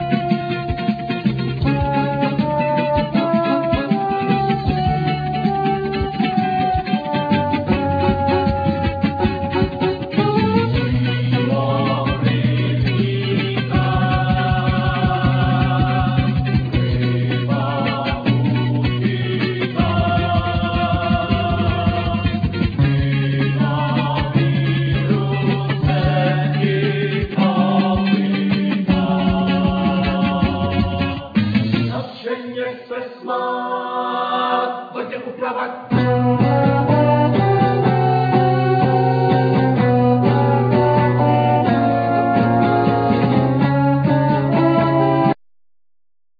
Vocal
Saxophone
Guitar
Bass
Drums
Violin
Piano